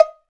Index of /90_sSampleCDs/NorthStar - Global Instruments VOL-2/PRC_Cowbells/PRC_Cowbells